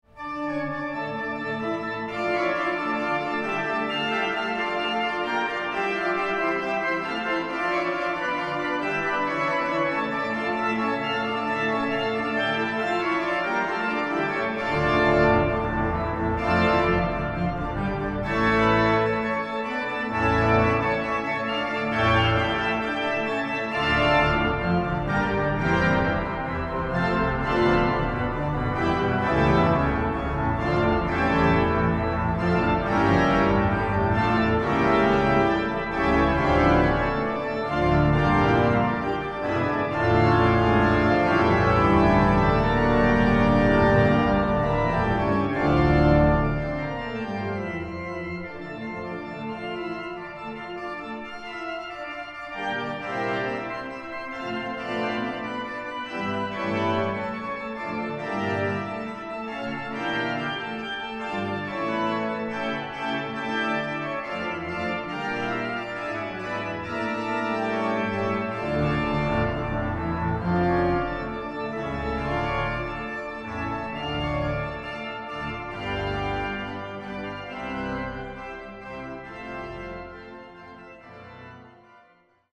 Konzert CD
WALCKER-Orgel von 1928.